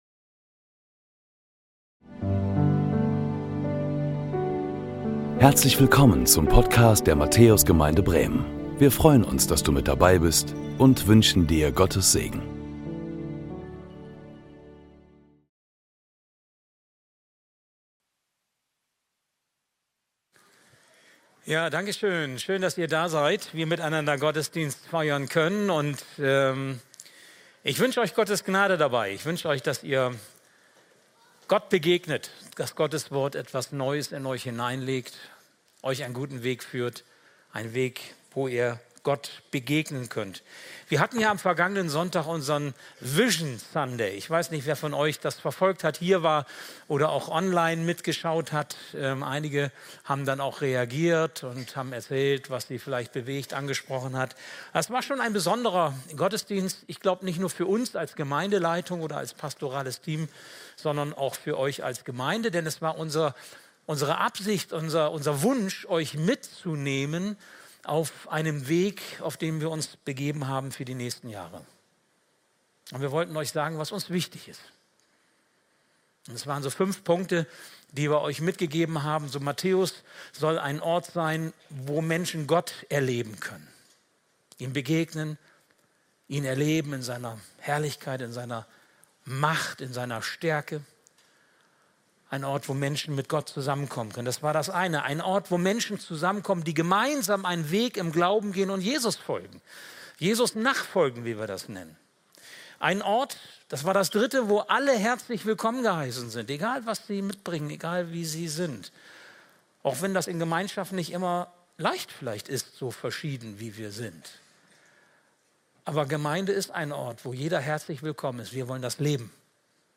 Heute setzen wir unsere Predigtreihe fort, in der es um völlige Hingabe geht. Wir freuen uns, dass du bei unserem modernen, 2. Gottesdienst dabei bist!